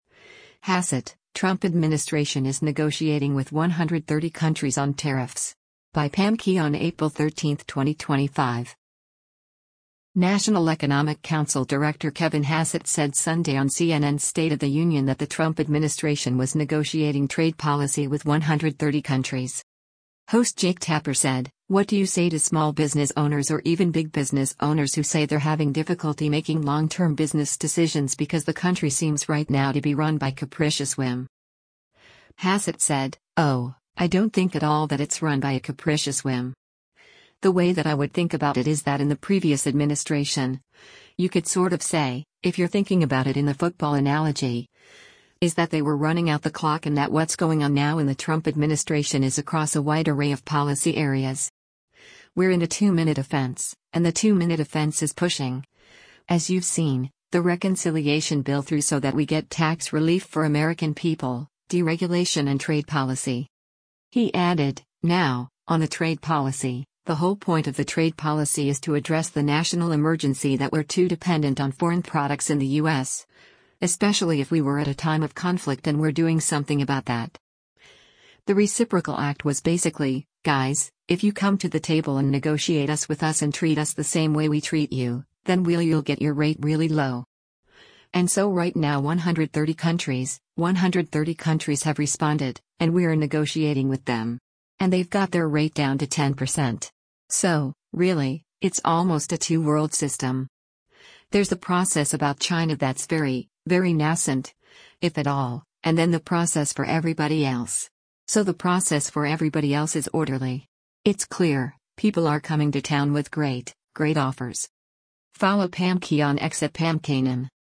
National Economic Council Director Kevin Hassett said Sunday on CNN’s “State of the Union” that the Trump administration was negotiating trade policy with 130 countries.